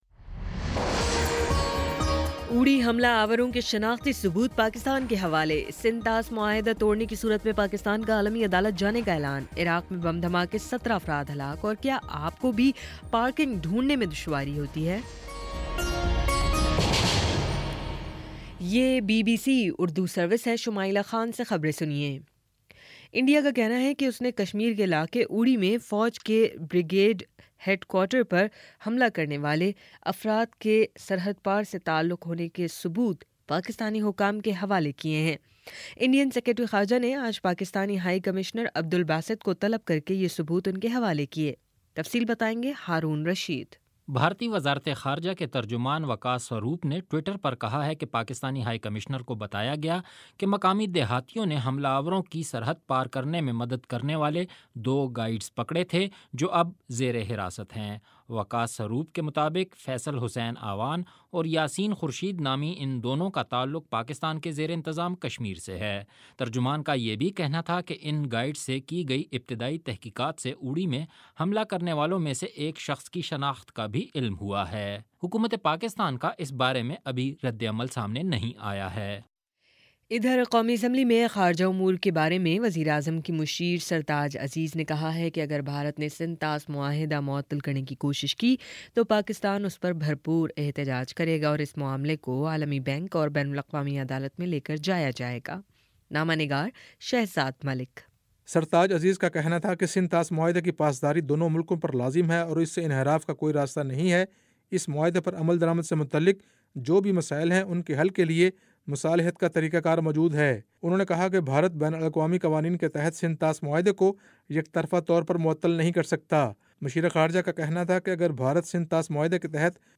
ستمبر27 : شام چھ بجے کا نیوز بُلیٹن